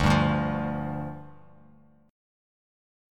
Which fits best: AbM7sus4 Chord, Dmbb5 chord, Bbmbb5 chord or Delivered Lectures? Dmbb5 chord